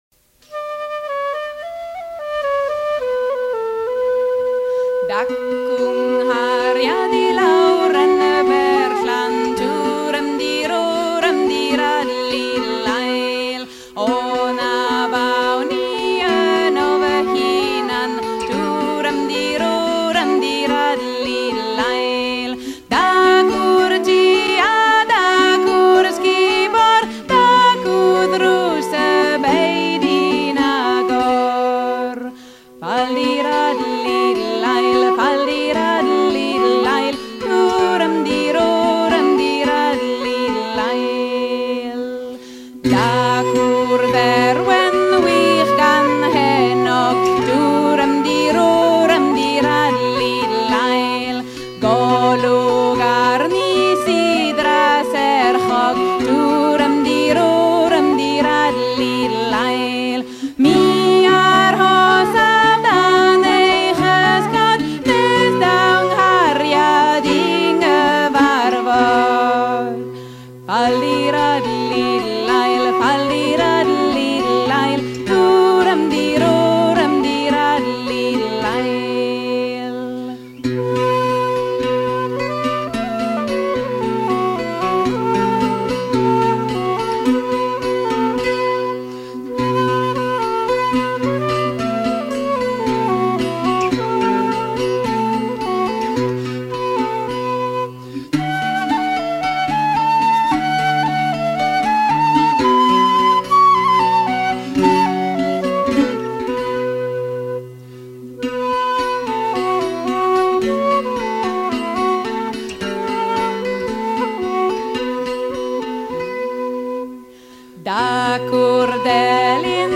chanson d'amour galloise